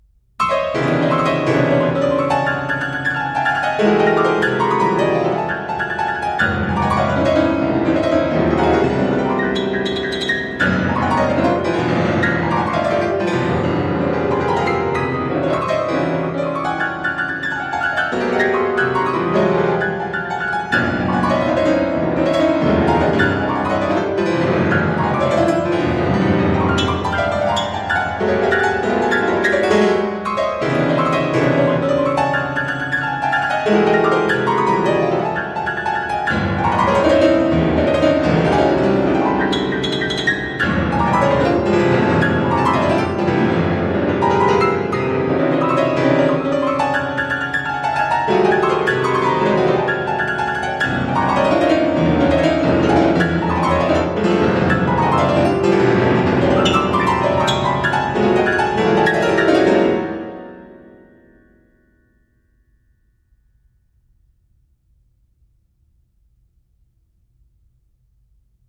• Genres: Solo Piano